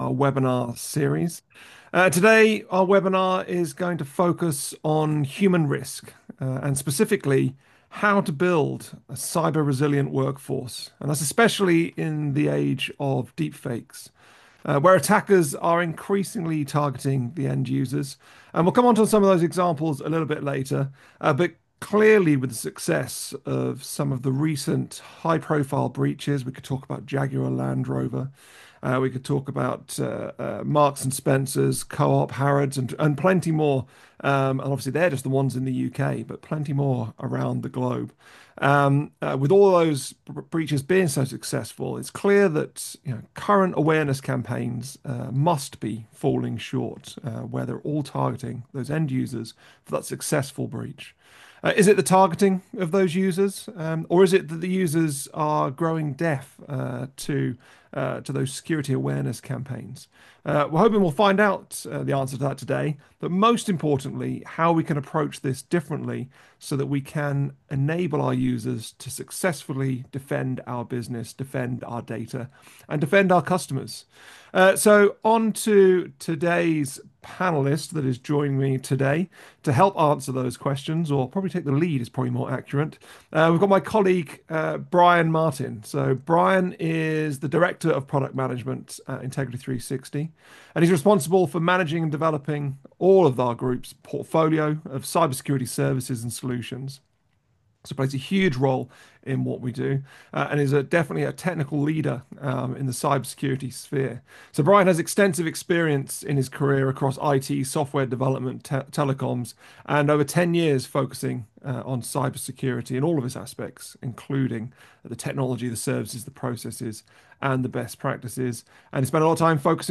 From targets to defenders: Building a cyber-resilient workforce in the age of deepfakes and advanced exploits This is the recording of our live webinar held on November 19th, 2025 Listen to audio View on demand recording